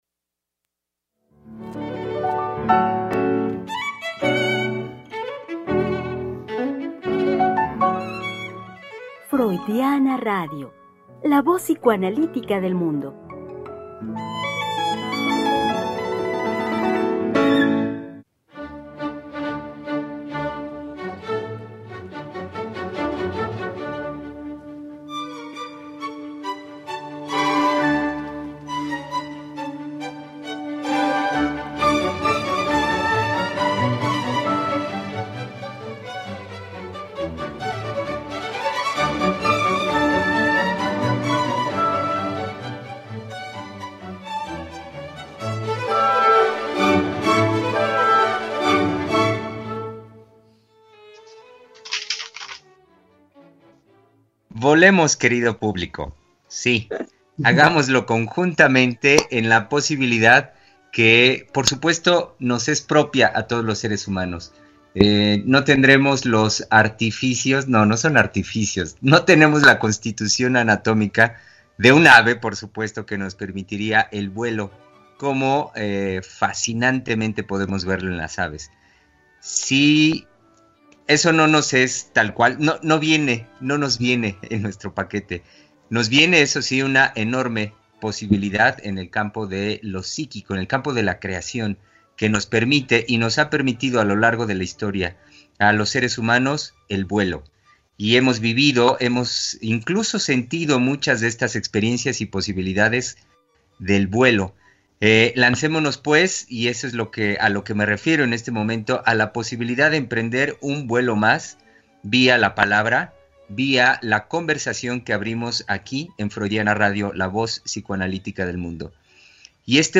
Tres Mujeres Psicoanalistas Hablando de la Vida Cotidiana.
Conversación con las psicoanalistas